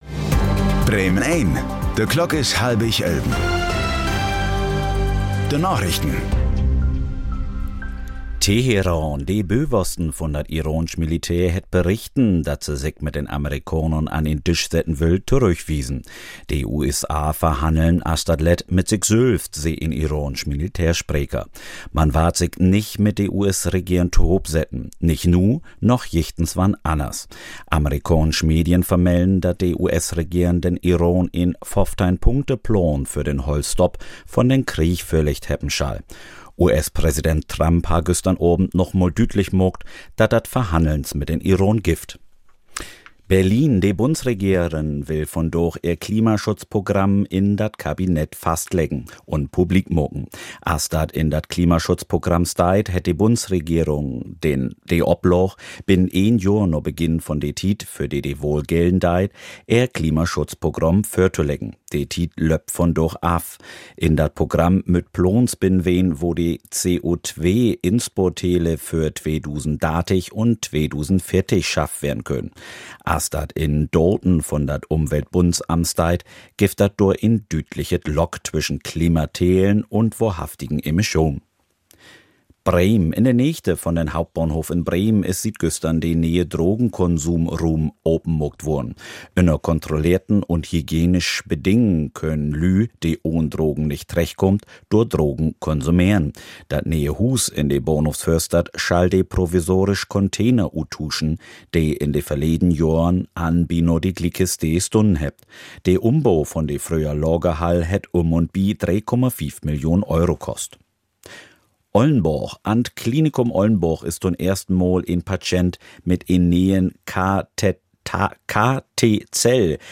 Plattdüütsche Narichten vun'n 25. März 2026